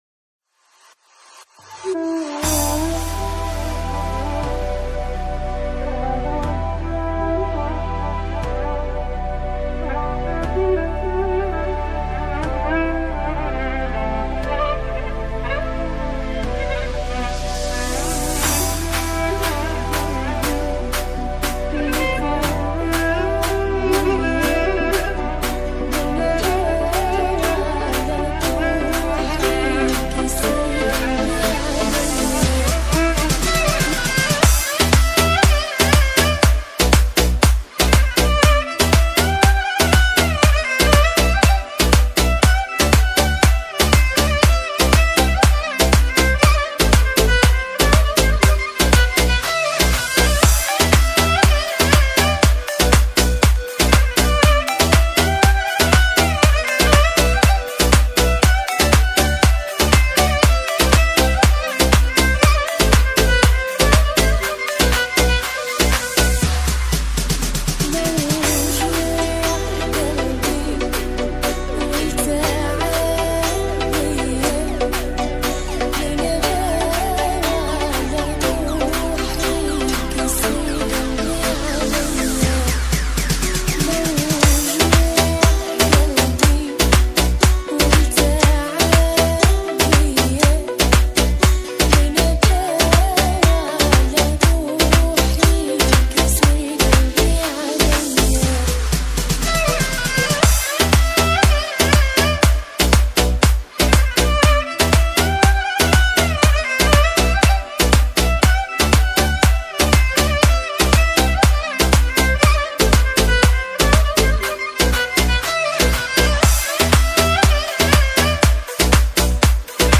Арабская__музыкаMP3_128K
Arabskaya__muzykaMP3_128K.mp3